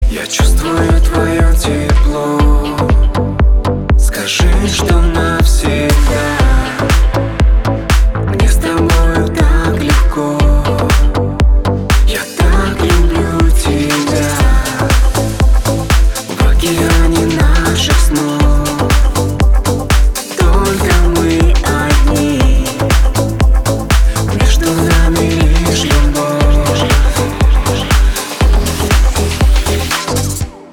• Качество: 320, Stereo
поп
красивая мелодия
дуэт